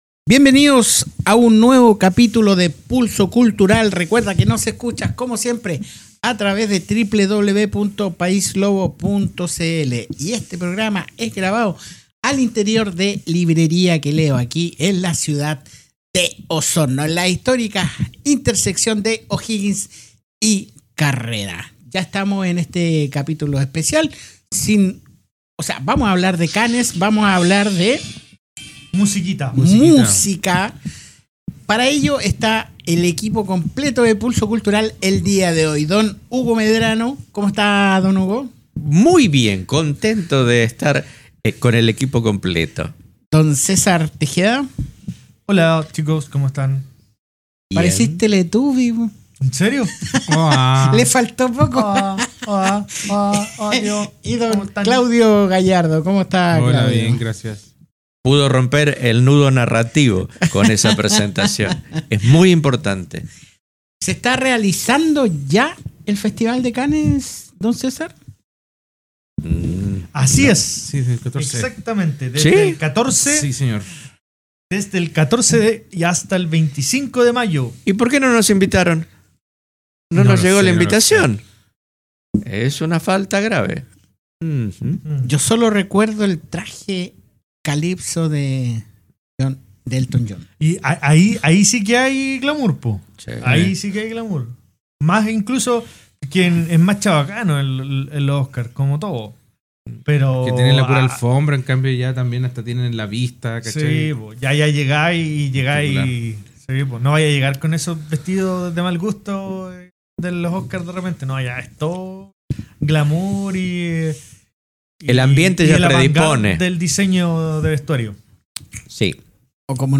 Un nuevo capitulo de Pulso Cultural grabado al interior de Librería Que Leo Osorno.